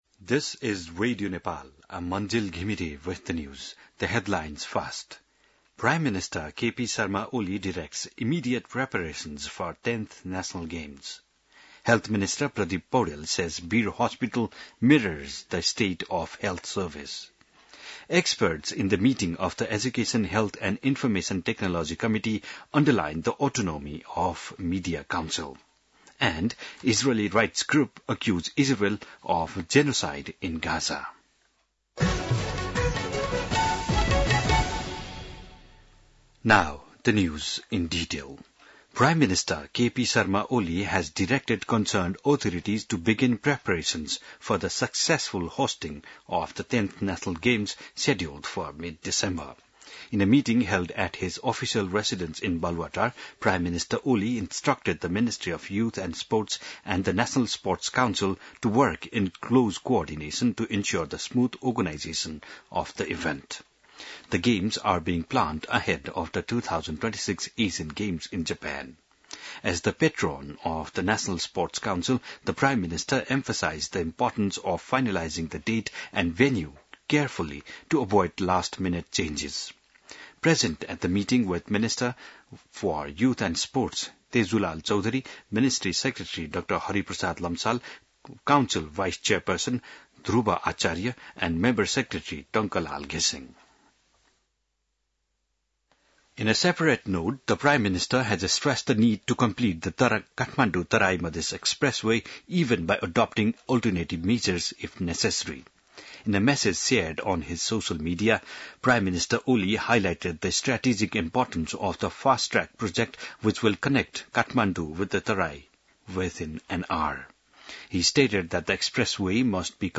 बिहान ८ बजेको अङ्ग्रेजी समाचार : १३ साउन , २०८२